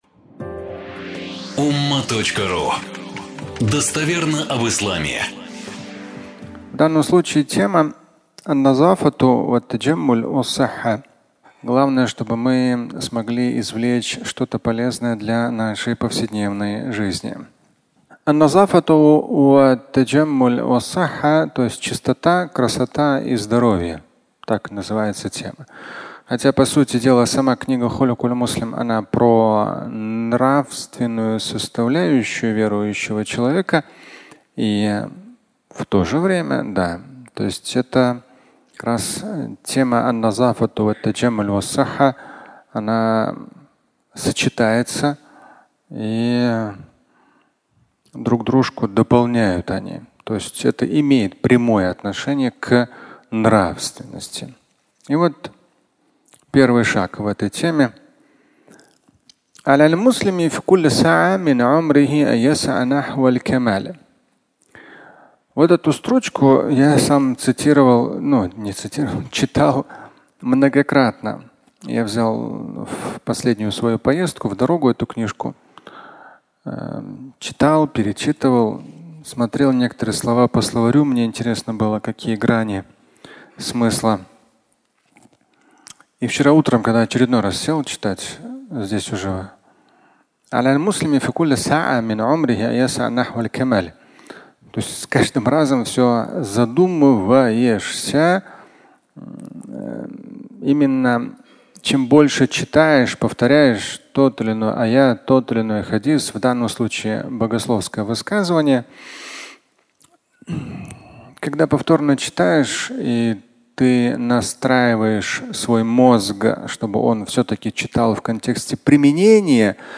Твой уровень (аудиолекция)